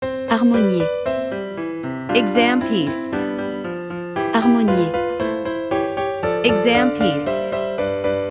Professional-level Piano Exam Practice Materials.
• Vocal metronome and beats counting